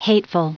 Prononciation du mot hateful en anglais (fichier audio)
Prononciation du mot : hateful